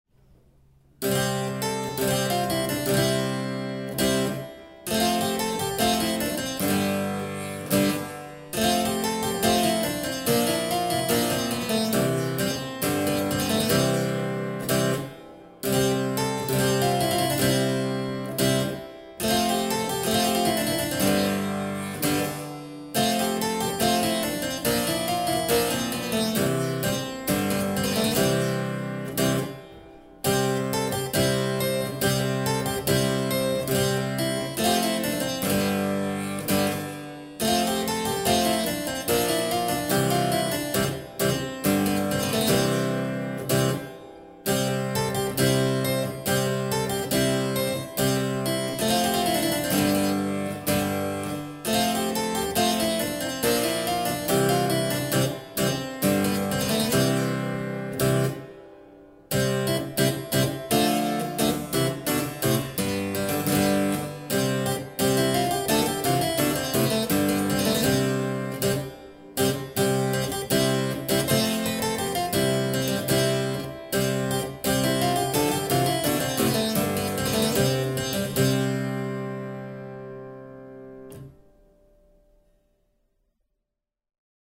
Clavicembalo
CLAVICEMBALO-PavanaInPassoEMezzo.mp3